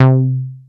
ACID L MID 1.wav